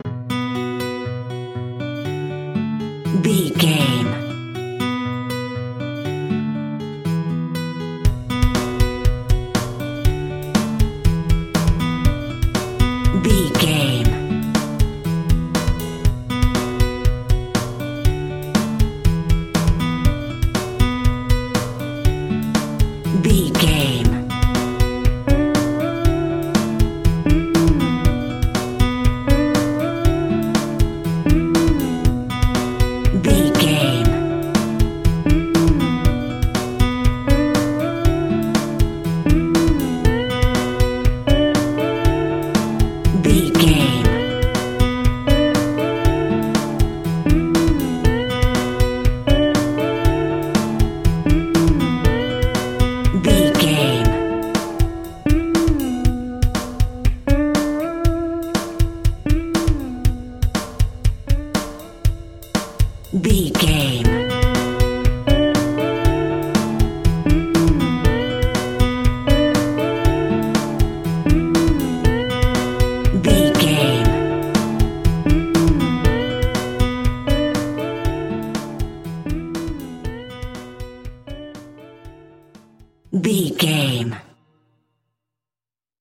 Aeolian/Minor
electronic
synths